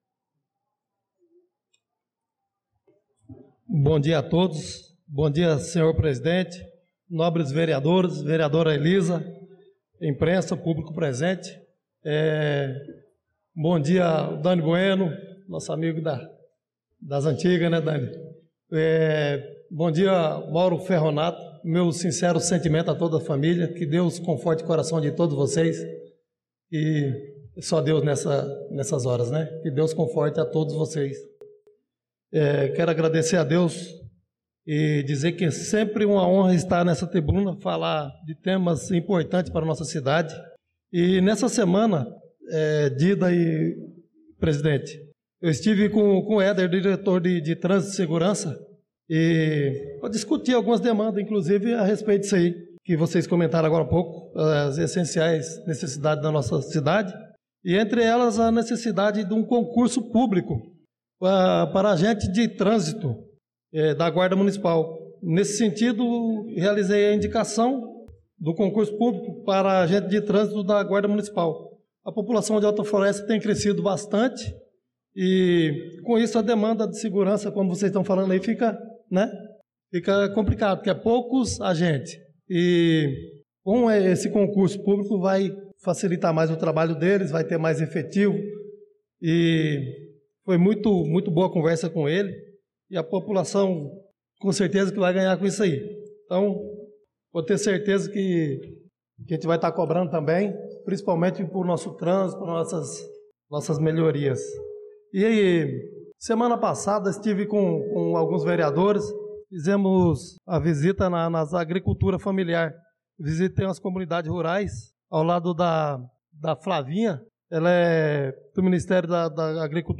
Pronunciamento do vereador Chicão Motocross na Sessão Ordinária do dia 25/02/2025